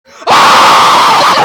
GRITO ESTOURADO - Botão de Efeito Sonoro